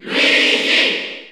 Crowd cheers (SSBU) You cannot overwrite this file.
Luigi_Cheer_Spanish_NTSC_SSB4_SSBU.ogg